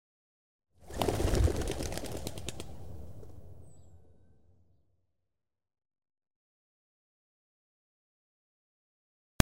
Звуки голубя
стая голубей рассыпается